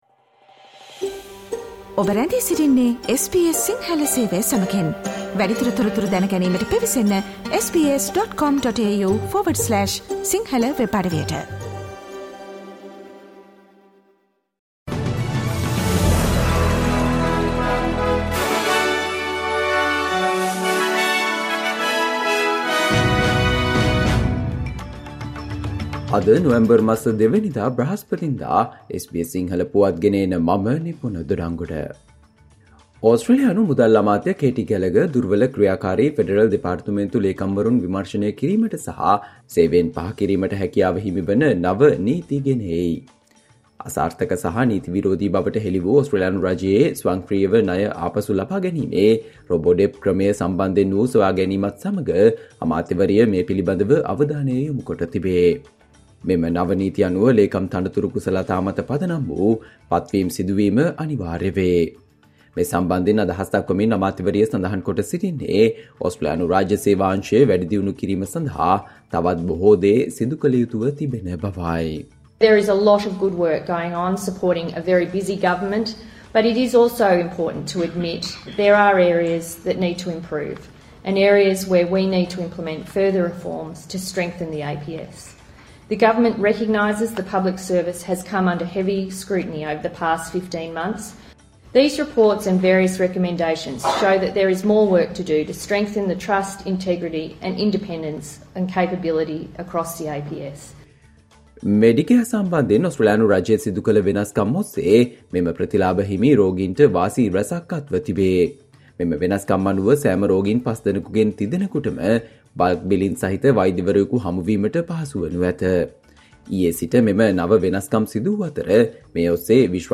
Australia news in Sinhala, foreign and sports news in brief - listen Sinhala Radio News Flash on Thursday 02 November 2023.